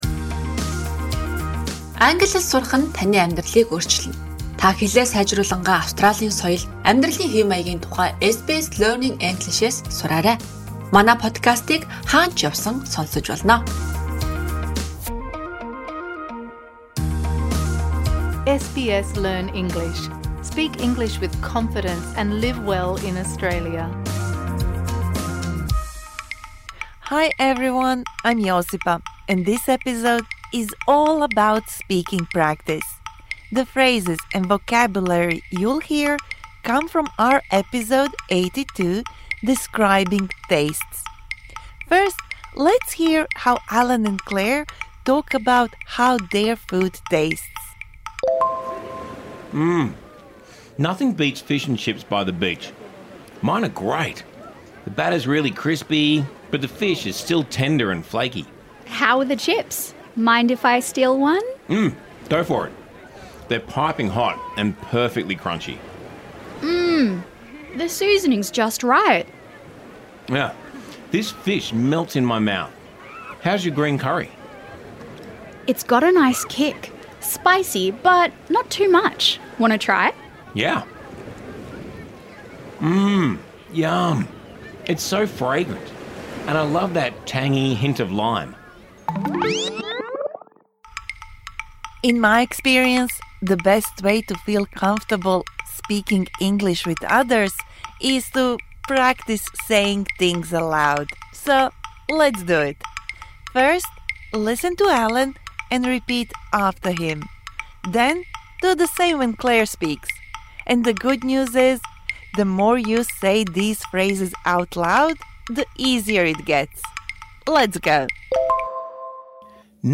This bonus episode provides interactive speaking practice for the words and phrases you learnt in Episode #81 Talking about changing habits Don't be shy - just try!